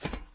StepSCP1.ogg